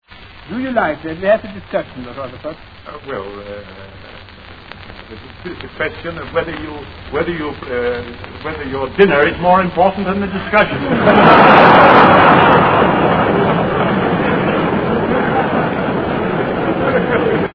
Hear Rutherford's Voice   (Question time.           0 min 17 sec, 34KB, MP3)